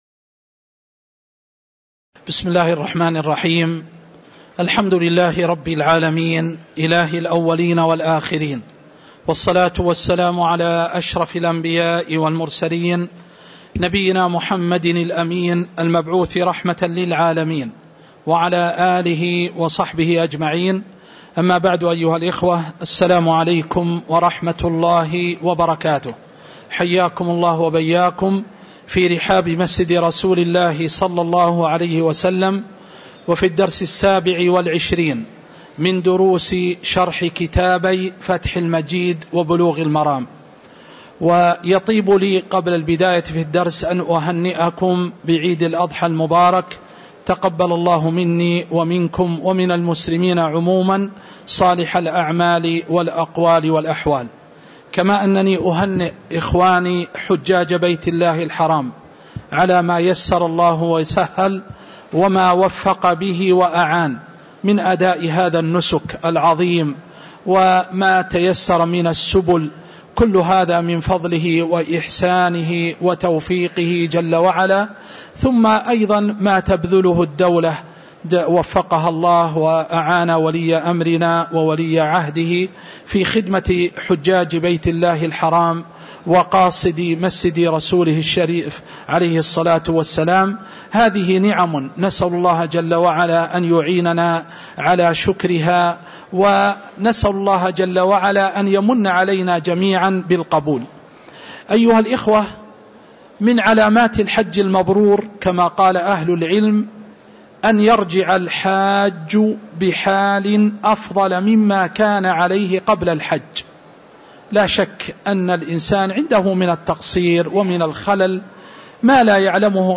تاريخ النشر ١٣ ذو الحجة ١٤٤٤ هـ المكان: المسجد النبوي الشيخ